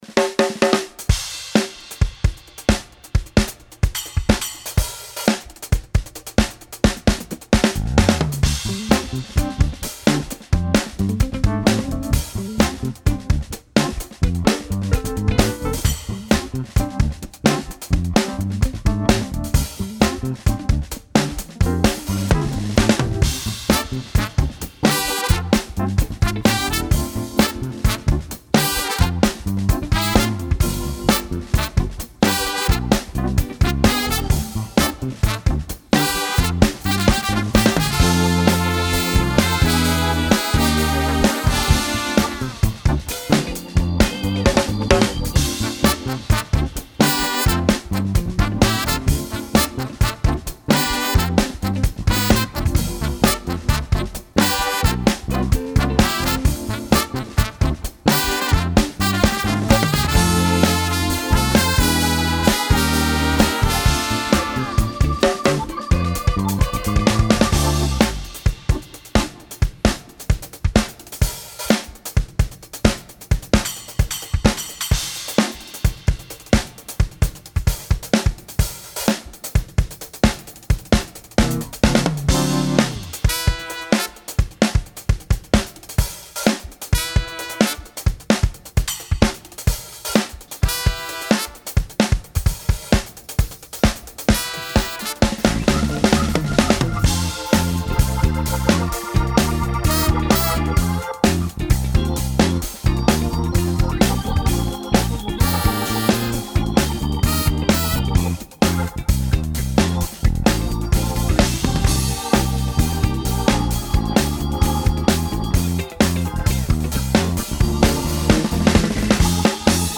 Funky Lines & JB-Feeling
energiegeladener Funk-Track